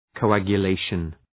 Προφορά
{kəʋ,ægjə’leıʃən} (Ουσιαστικό) ● πήξη